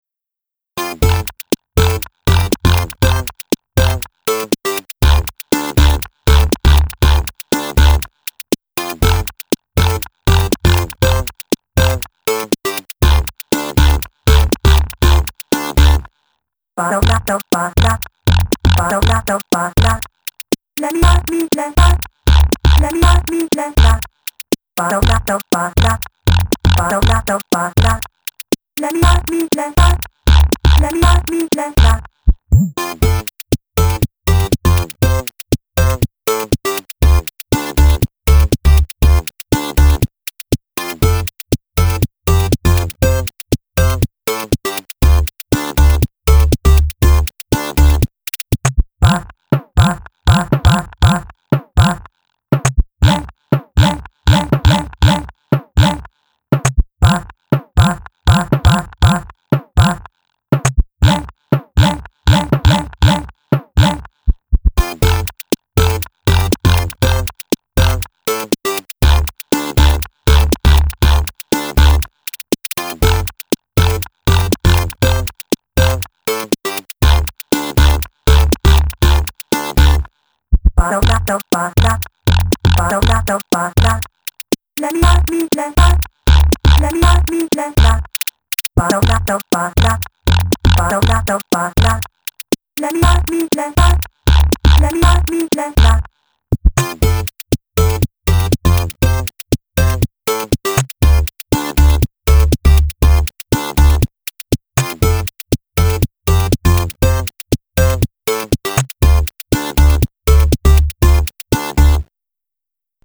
Oddball 8-bit electronics with quirky voice samples.